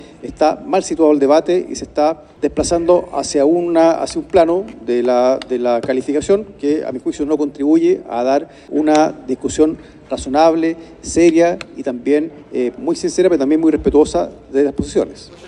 En tanto, desde el PC, el diputado Luis Cuello criticó a Tohá por exacerbar el debate y derivarlo en descalificaciones.